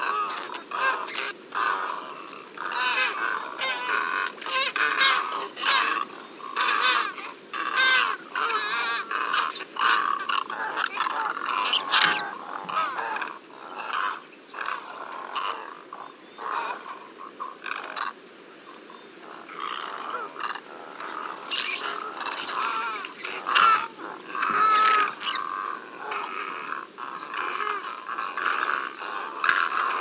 Grande aigrette. Mince, anguleux mais élégant, cet échassier d’un blanc pur se remarque de très loin. Silencieuse.
grande-aigrette.mp3